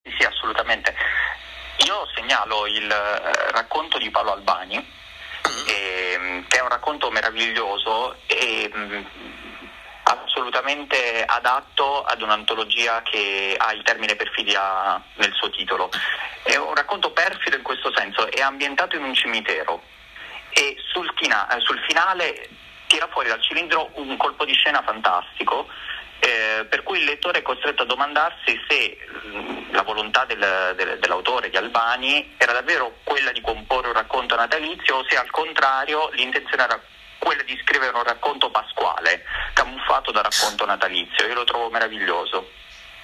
alle ore 17:30 a Fahrenheit, trasmissione